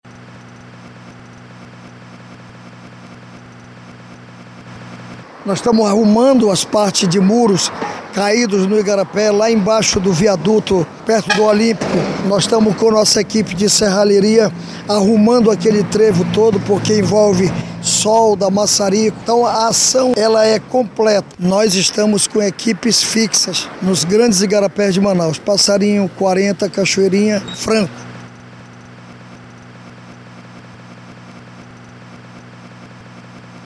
Mais de 500 homens executaram os serviços de poda, capina, varrição, raspagem, desobstrução de bueiros, desassoreamento, restauração de calçadas e meios-fios nos espaços. Os trabalhos tiveram maior intensidade por conta do serviço de prevenção contra enchentes. Como destaca o chefe da pasta, Sabá Reis.
Sonora-Saba-Reis-Secretário-da-Semulsp.mp3